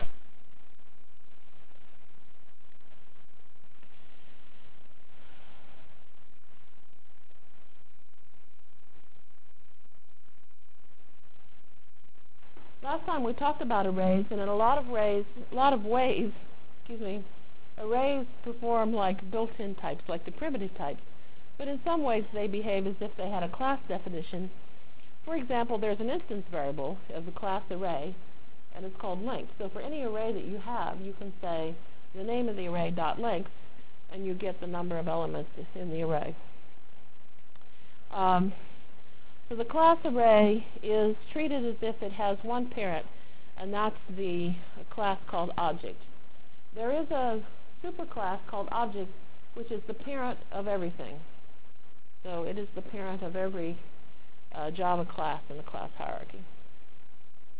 From Jan 27 Delivered Lecture for Course CPS616